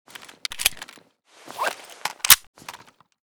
vz61_reload.ogg